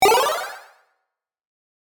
Download 8 Bit sound effect for free.
8 Bit